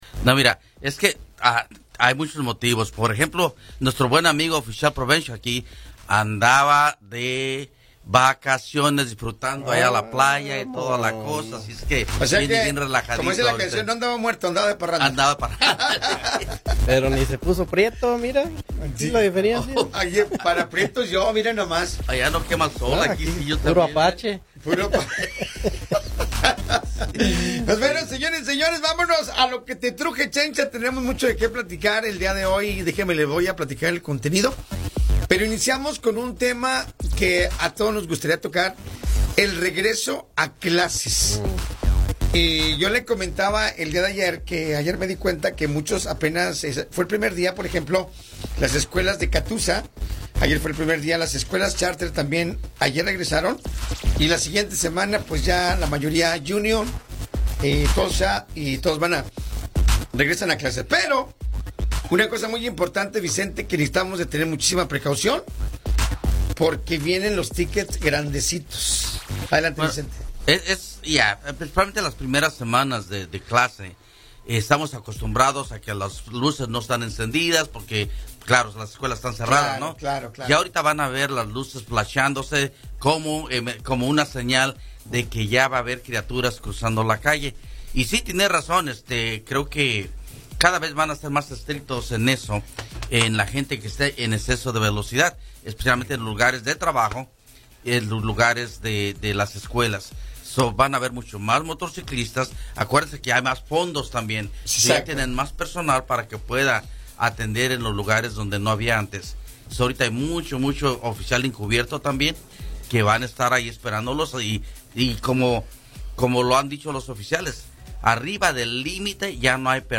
🏙 Christian Bengel, Concejal Municipal del Gobierno de Tulsa, explica el avance que lleva la ciudad en la atención al creciente problema de las personas en situación de calle.